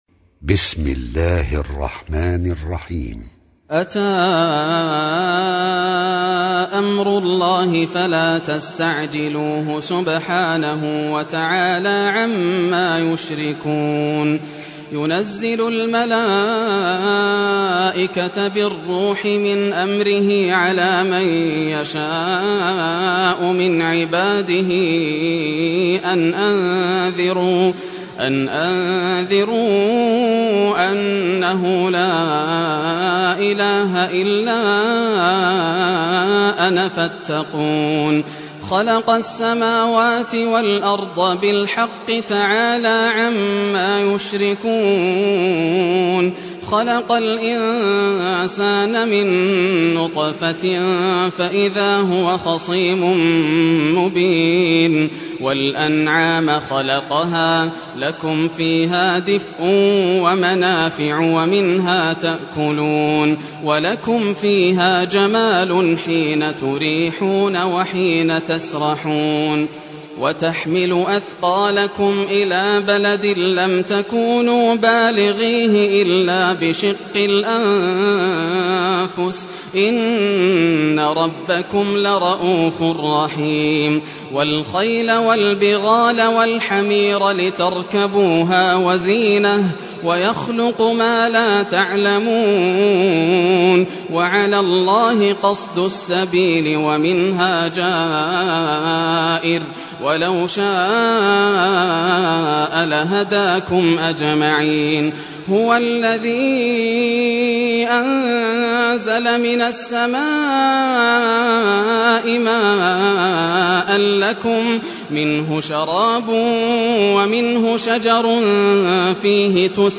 Surah Nahl Recitation by Yasser Al Dosari
Surah Nahl, listen or play online mp3 tilawat / recitation in Arabic in the beautiful voice of Sheikh Yasser al Dosari.